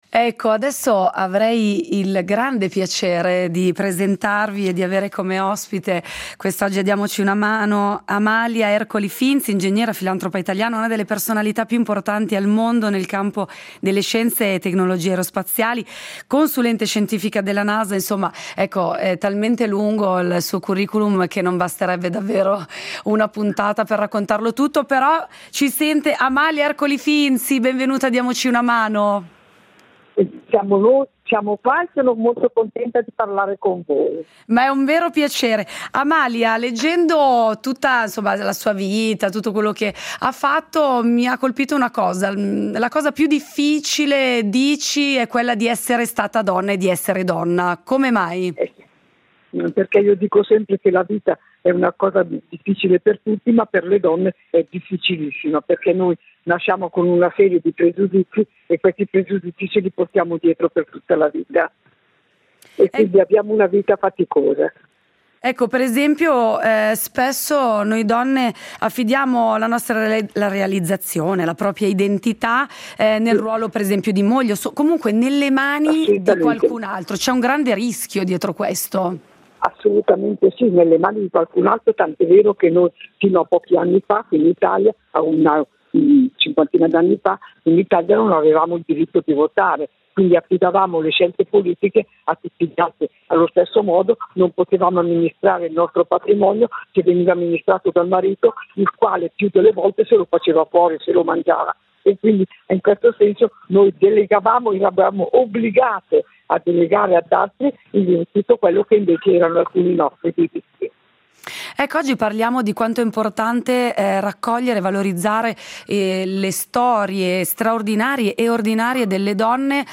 Incontro con Amalia Ercoli-Finzi, scienziata e ingegnera aerospaziale